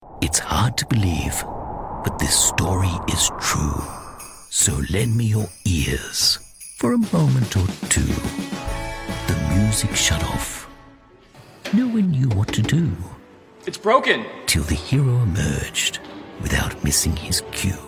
Movie Trailers
Neumann TLM 103 mic